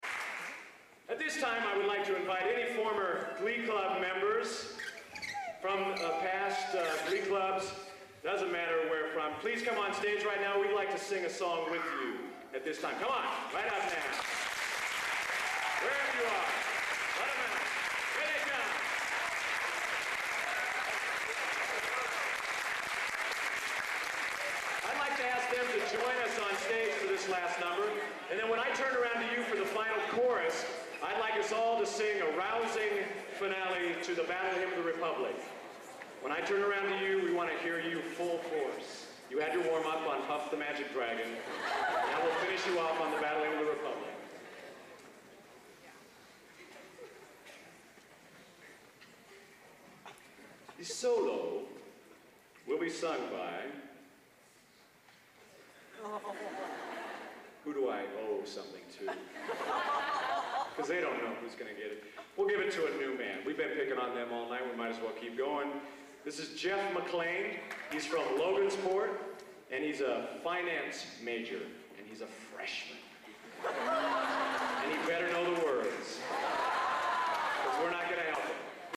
Location: Purdue Memorial Union, West Lafayette, Indiana
Genre: | Type: Director intros, emceeing